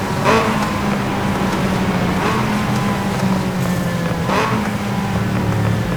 slowdown_slow.wav